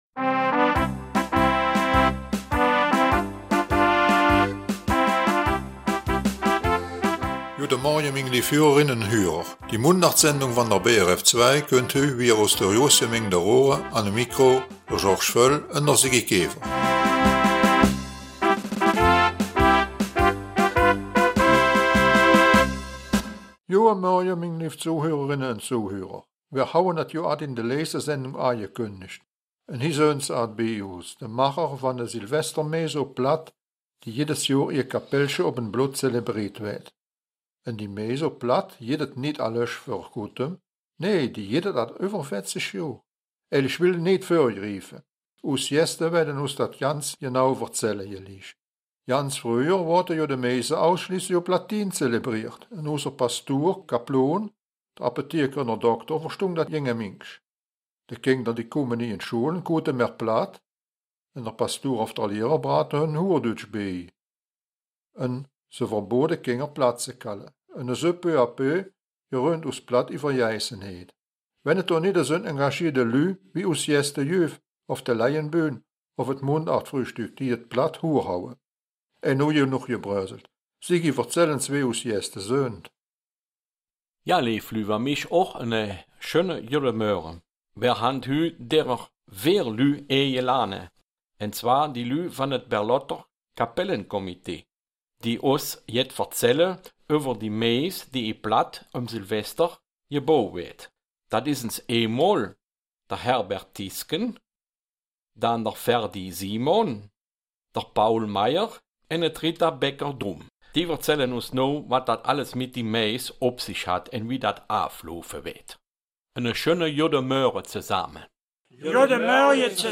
Die Mundartsendung vom 14. Dezember aus Raeren bringt folgendes Thema: Silvestermesse auf Platt im Kapellchen in Berlott.